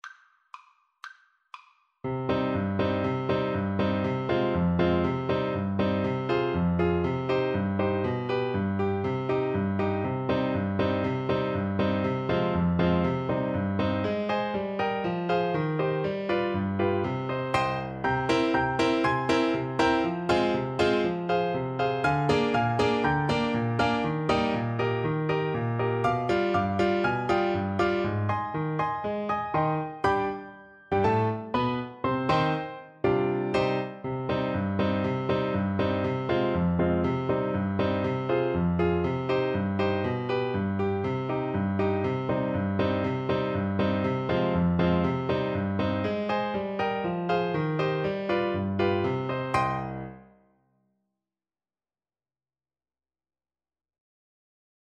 Allegro (View more music marked Allegro)
Classical (View more Classical Viola Music)